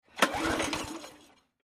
Lawnmowers
in_lawnmower_pull_02_hpx